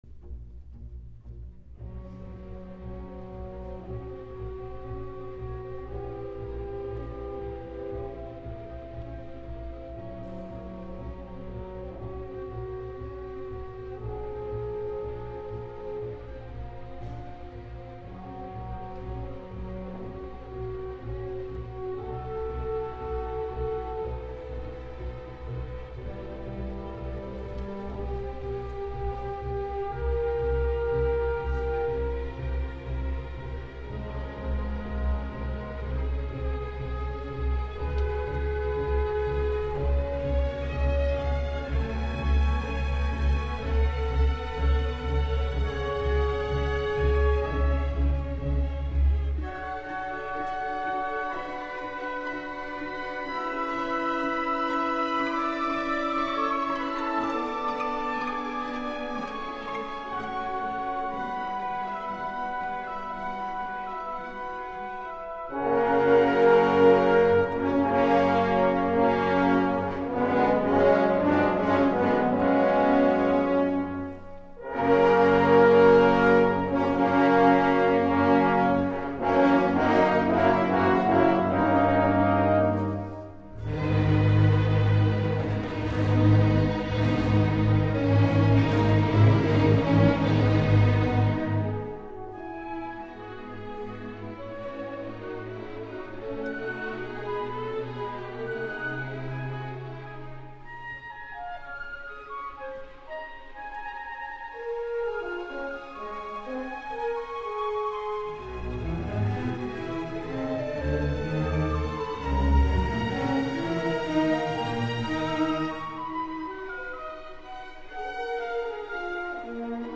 第33回定期演奏会（港北シンフォニーコンサート）
2004年2月1日 横浜みなとみらいホール（大ホール）